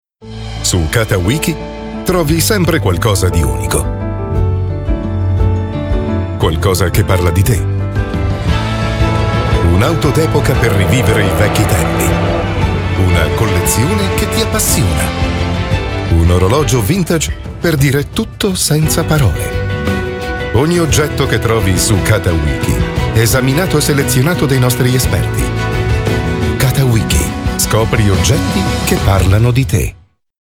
Comercial, Travieso, Maduro, Cálida, Empresarial
Comercial